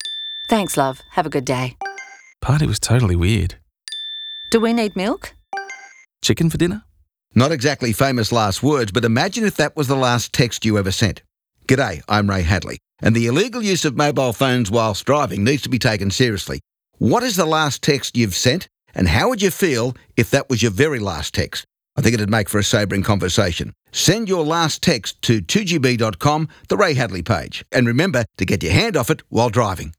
In the 30 second radio commercial, Hadley asks drivers to think about the last text message they sent and then imagine it as the last text they would ever send.